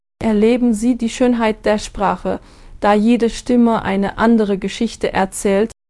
de-female.mp3